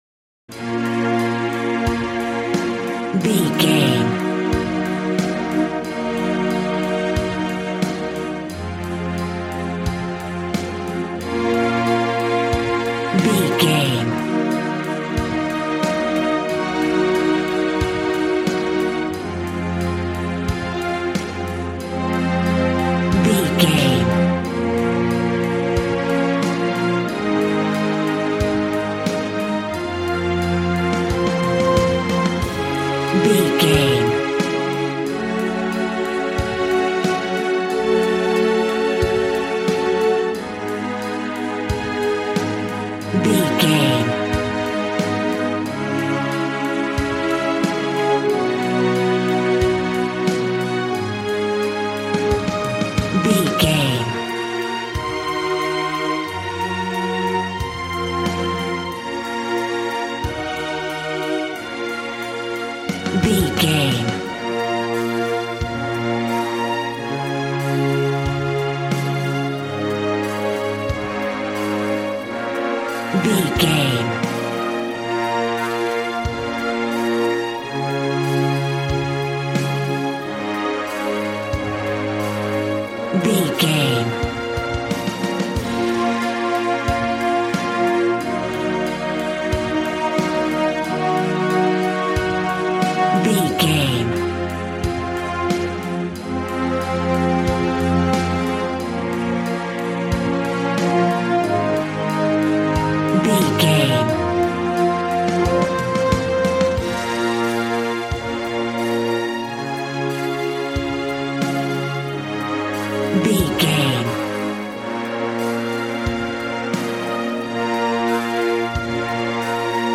Aeolian/Minor
B♭
dramatic
epic
strings
violin
brass